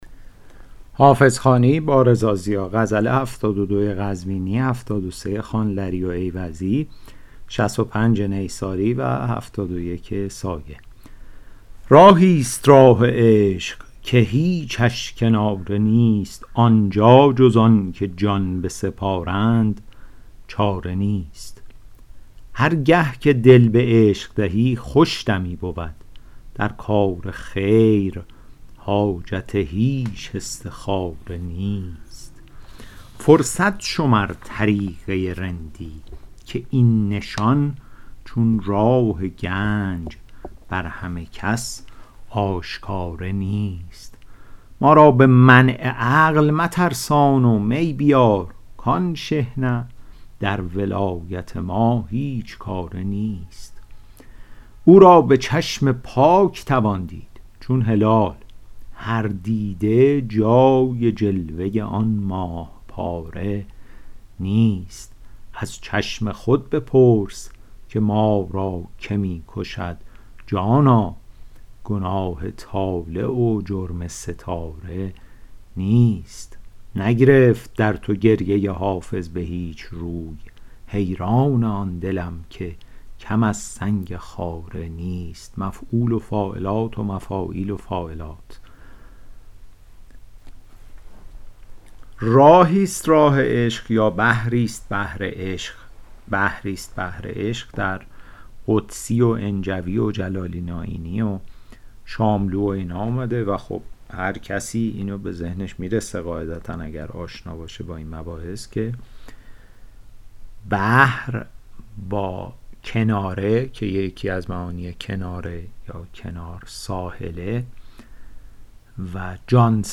شرح صوتی غزل شمارهٔ ۷۲